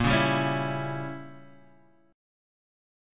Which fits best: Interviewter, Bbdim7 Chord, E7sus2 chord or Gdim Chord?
Bbdim7 Chord